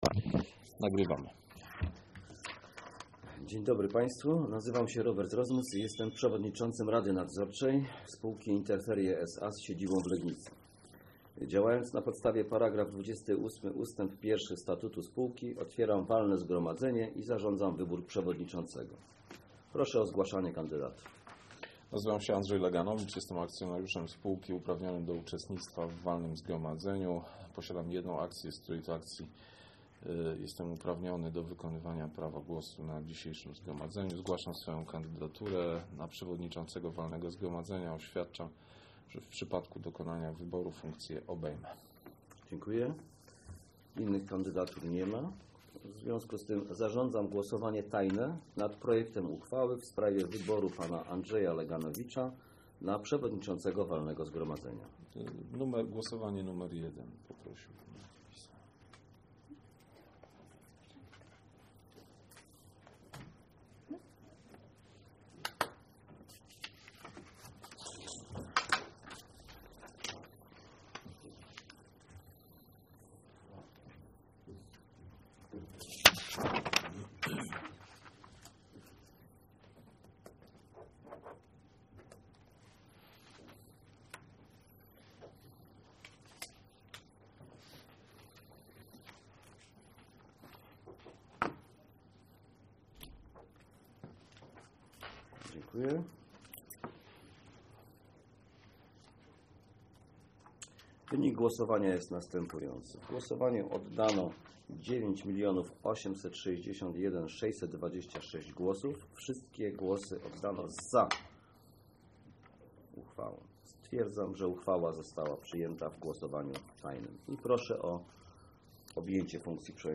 Nadzwyczajne Walne Zgromadzenie 08.03.2016
Nagranie z NWZ